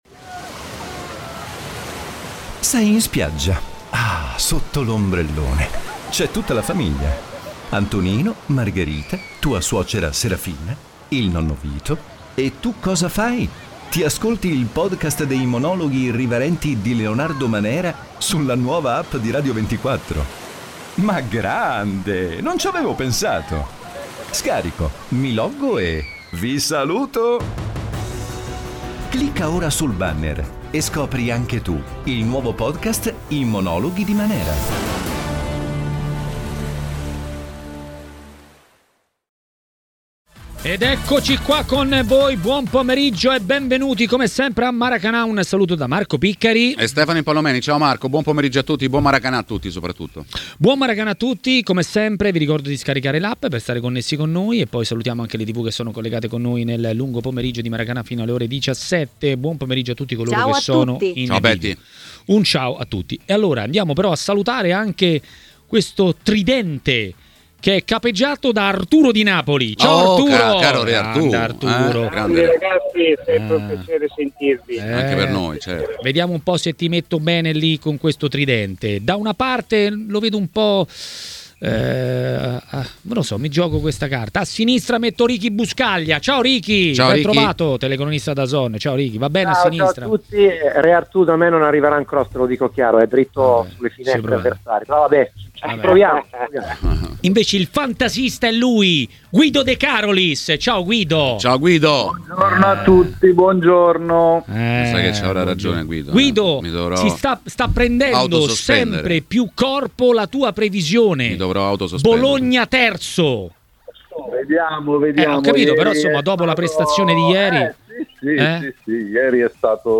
A Maracanà, nel pomeriggio di TMW Radio, è arrivato il momento dell'ex calciatore e giornalista Stefano Impallomeni.